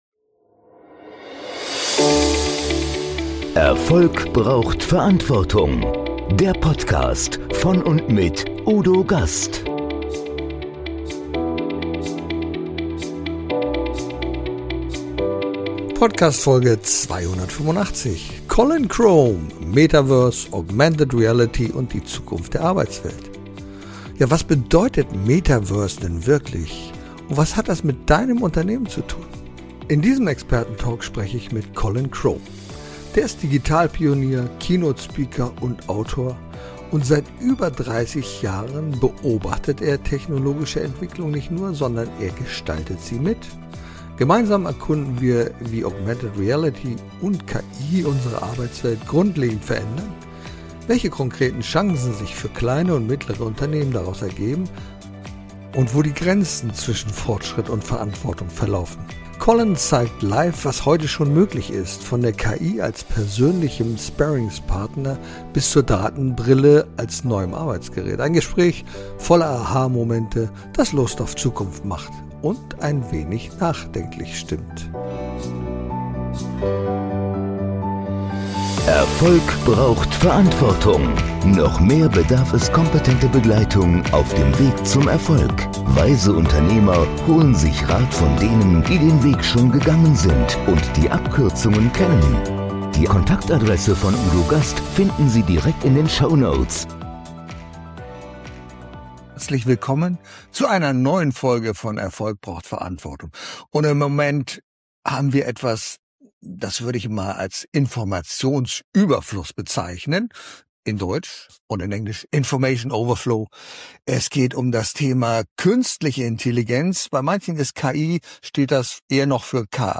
Ein Gespräch voller Aha-Momente, das Lust auf Zukunft macht – und nachdenklich stimmt.